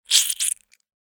Download Blood sound effect for free.